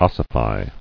[os·si·fy]